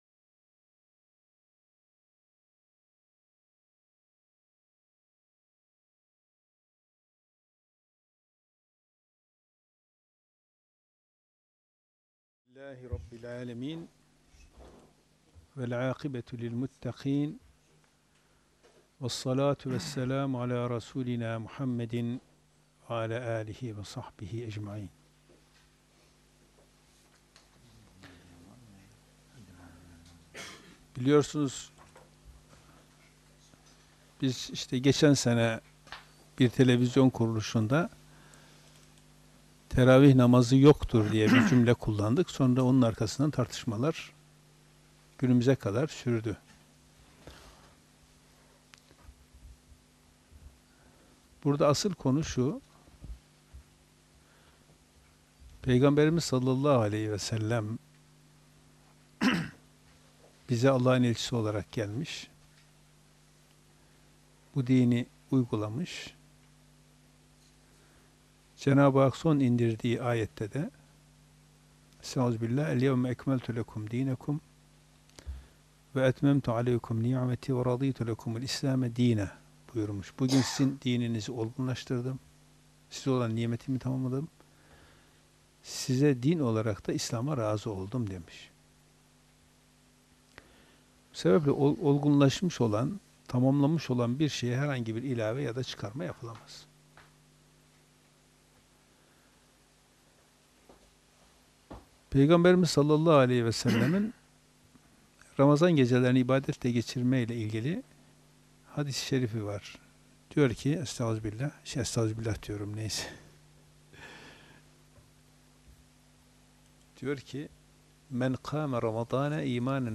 NOT: Sohbet sırasında, numaraları ve isimleri söylenmeyen sure-ayetleri anlayabildiğim kadarıyla yazıyorum ve başlarına “?” işareti ve dakika yazıyorum.